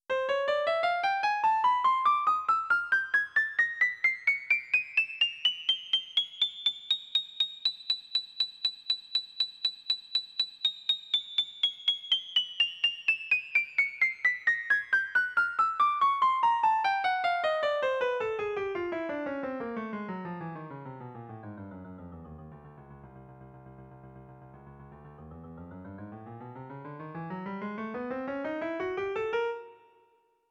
Next we connect additional musical parameters to the sine function, namely, note duration, dynamic, and panning.
Melodic contour from sine wave also mapped to duration of notes
# pitch contour, duration, dynamics (volume), and panning.